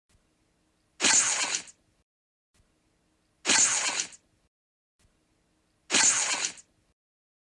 Spider-Man testing his web shooters